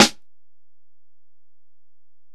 Snare (5).wav